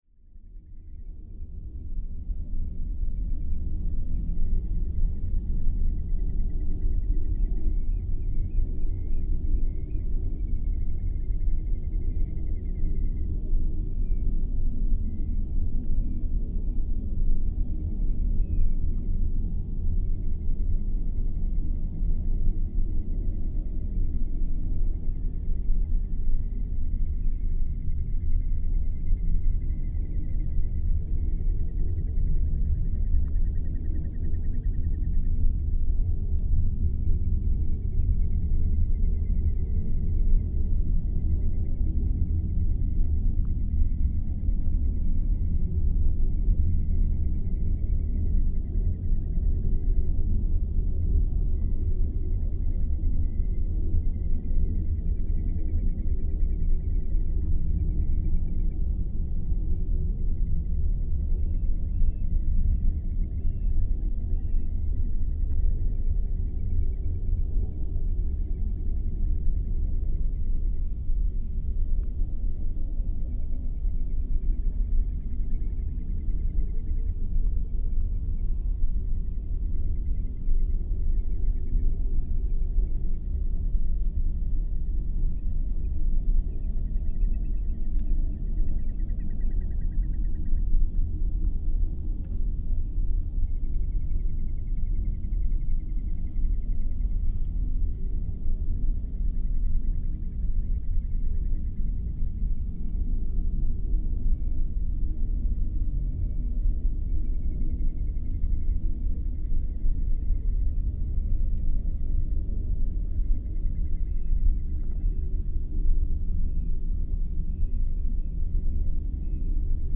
The rumbling noise from blowing wells is so loud that it can be easily heard in far distances (up to 20-30km, depends of condition).
The power station was in about 10 km distance, but the blowing wells were somewhere between in 10-15km distance, probably behind the mountain Hengill. This recording was made at 3am, 24th of July 2013 at Mosfellsheiði . The weather was calm, but cold, very humid and fogy. Keep in mind, this is a “quiet nature recording” so you should not play it loud. On the field the birdsong was barely audible.